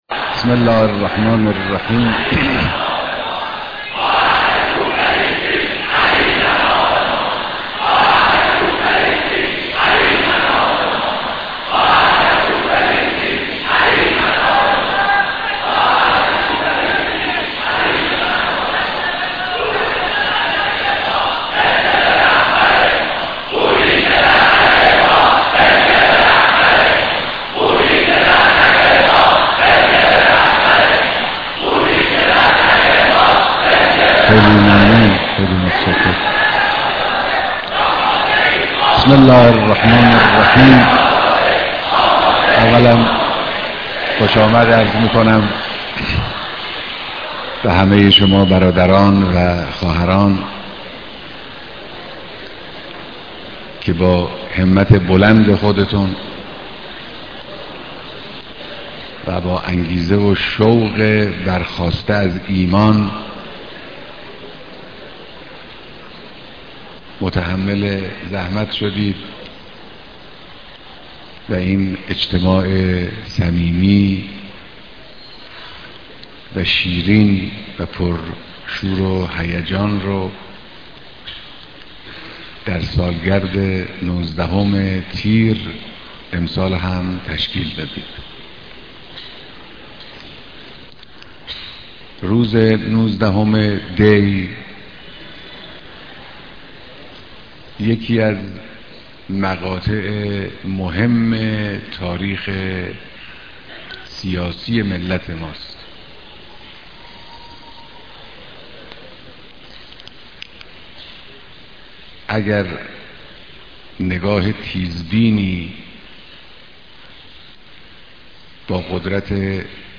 بيانات در ديدار مردم استان قم